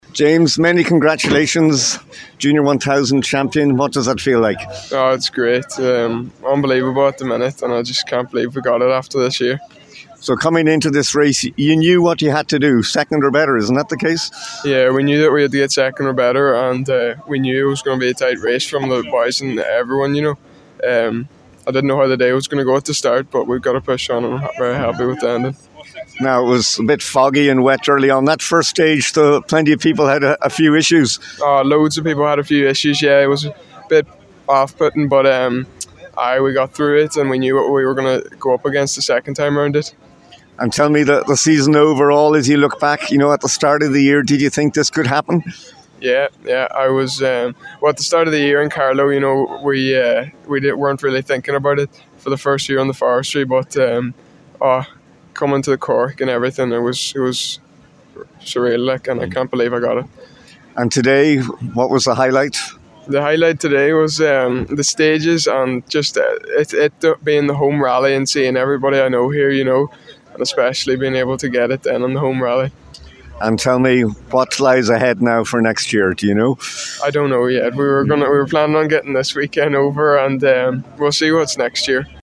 Donegal Forestry Rally – Reaction from the finish line